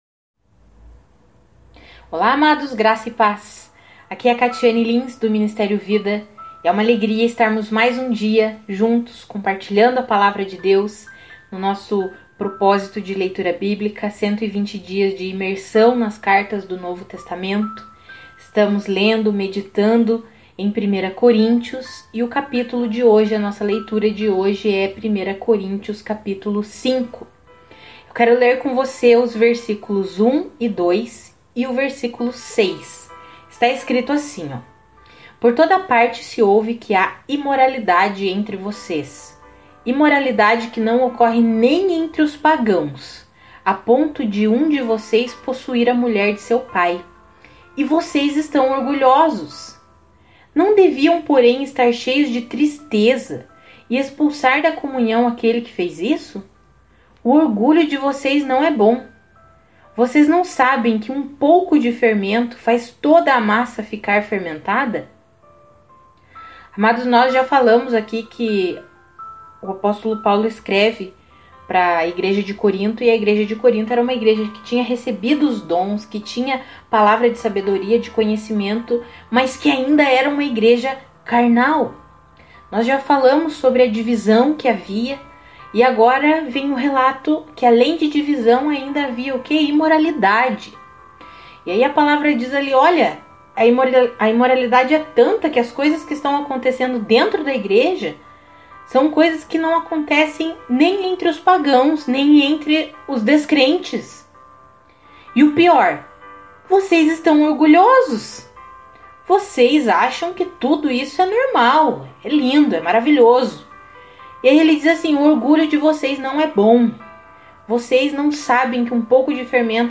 Além da leitura Bíblica nós fornecemos um devocional escrito e um devocional em áudio, todos os dias, para edificar a sua fé.